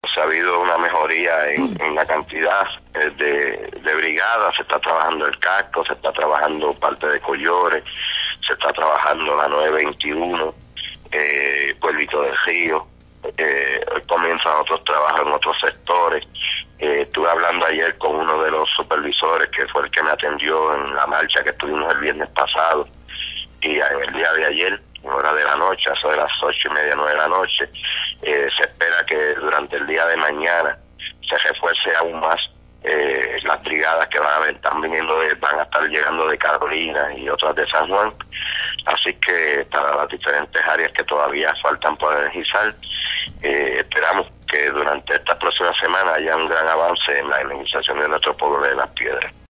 Alcalde de Las Piedras, Miguel “Mickey” López Rivera